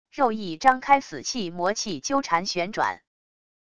肉翼张开死气魔气纠缠旋转wav音频